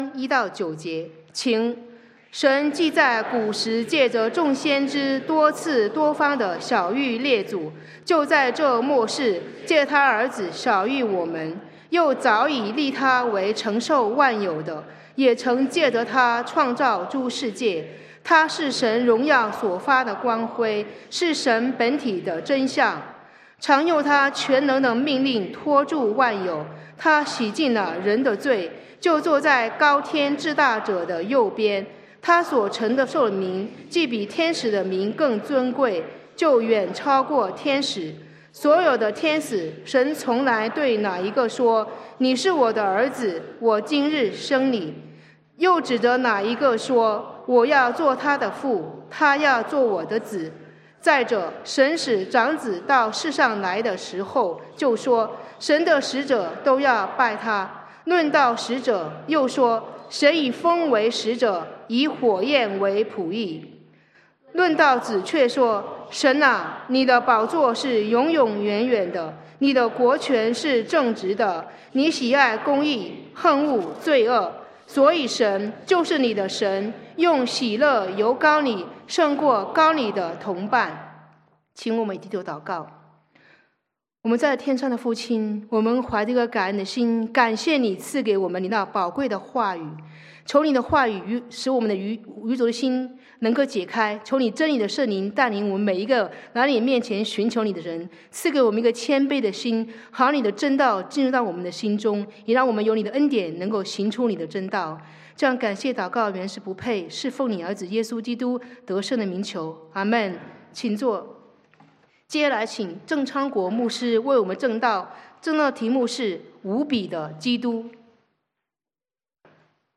主日證道